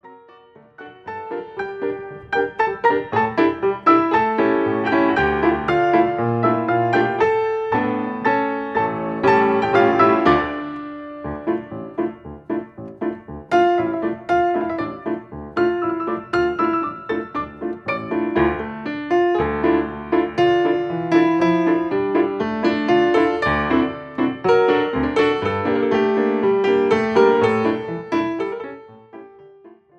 soaring harmonies and soothing melodies